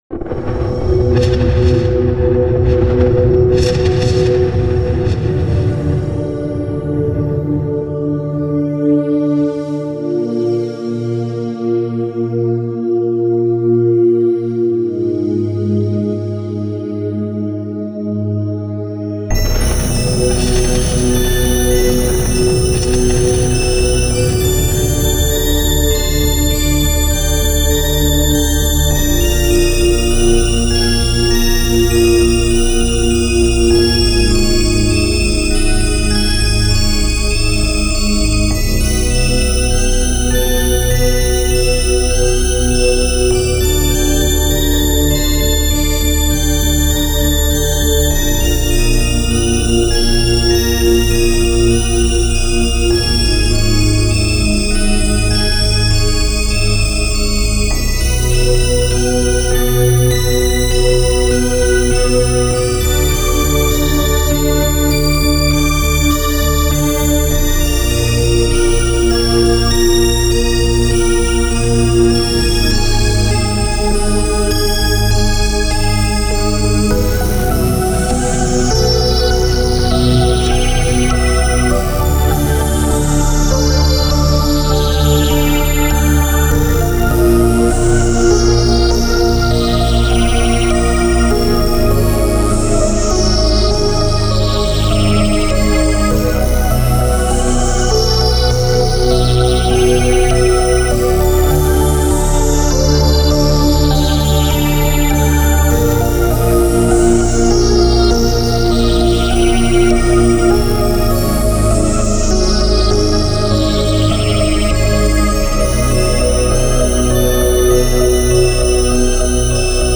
| A musical theme from a fictional '80s horror film, Haunted Lake. With a musical style that is influenced by synth music from 80s movie theme composer such as John Carpenter